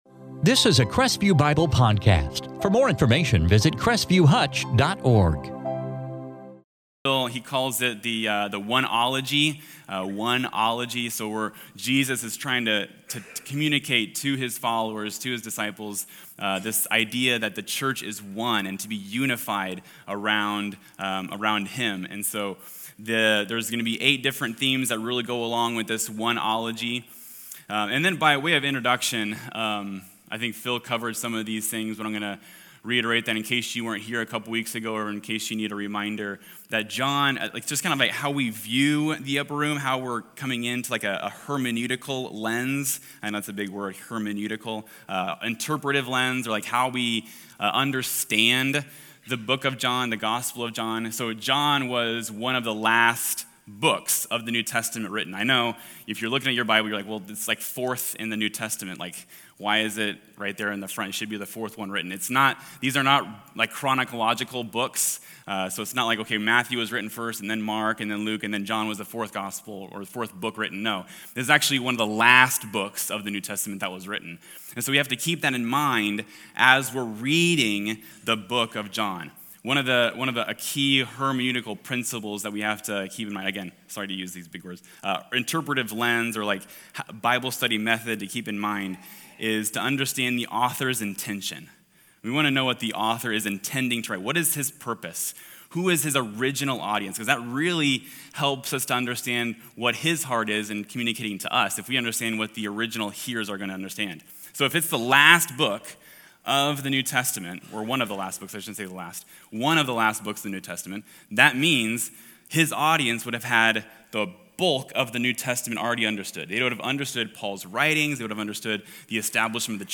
2025 One-ology John 14:12-14 In this sermon